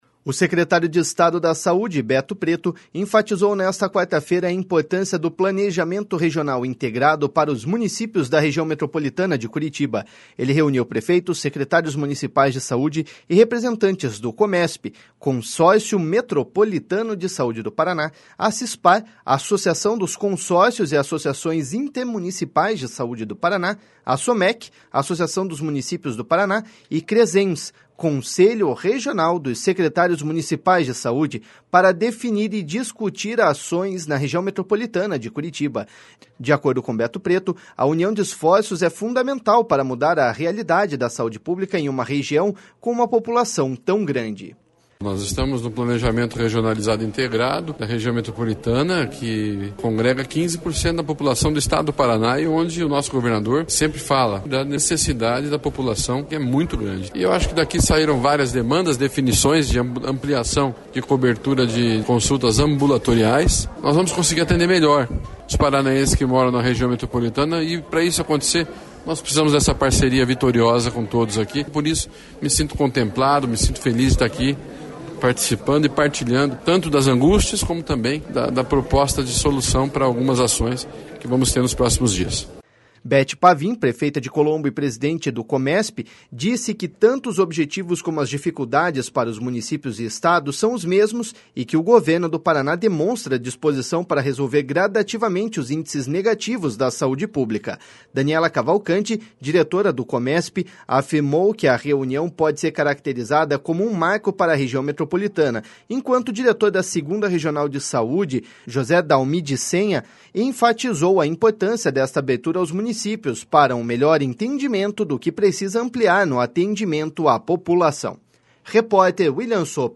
De acordo com Beto Preto, a união de esforços é fundamental para mudar a realidade da saúde pública em uma região com uma população tão grande.// SONORA BETO PRETO.//